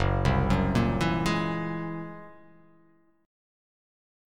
F#9b5 Chord